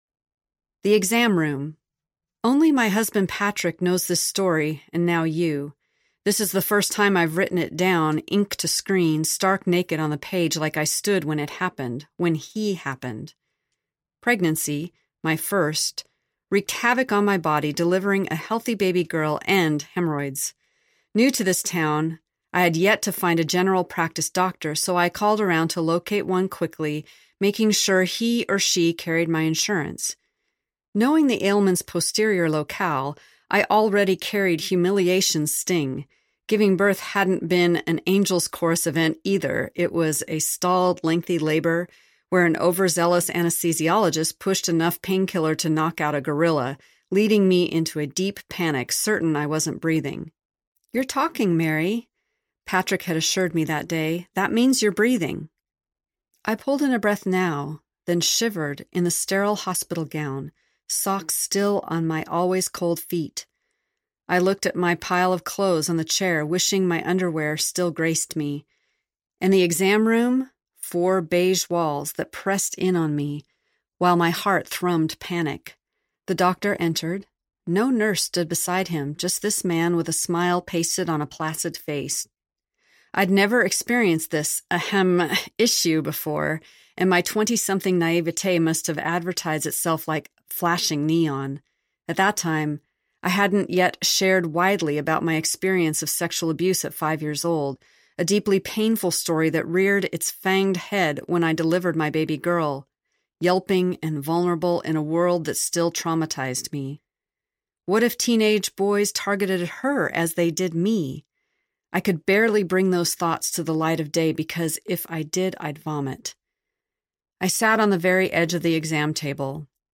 We Too Audiobook